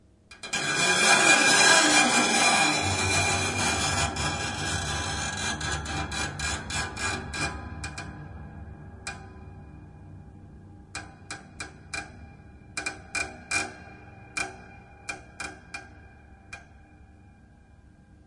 SCRAPE S钢琴音板样本 " scrapes03
描述：录音是用2个mxl 990话筒录制的，一个靠近琴弦，另一个在8英尺远的地方。
这些都是立体声录音，但一个通道是近处的话筒，另一个是远处的话筒，所以为了获得最佳效果，可能需要进行一些相位和平移调整。
Tag: 音响 效果 FX 恐怖 工业 钢琴 音响 声音效果 音板